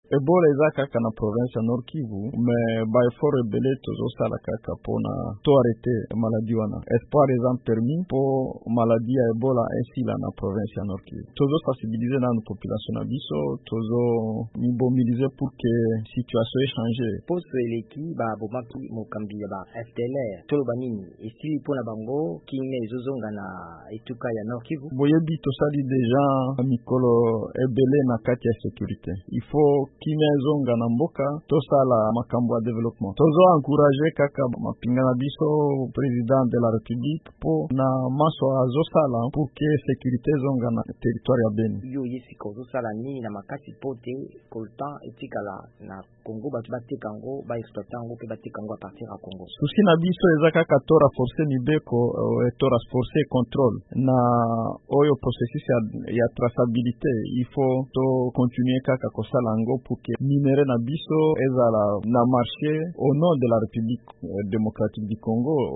Carly Nzanzu Kasivita, gouverneur ya Nord-Kivu, azalaki moko na bakambi ya bituka baye bazalaki na président Félix Tshisekedi na mobembo na ye awa Etats-Unis. VOA Lingala etunaki mituna na Carly Nzanzu.